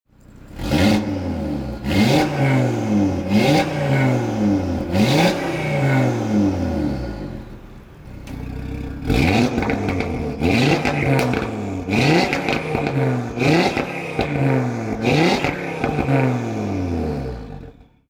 LISTEN TO THE 5-CYLINDER SYMPHONY!
OEP-revs.mp3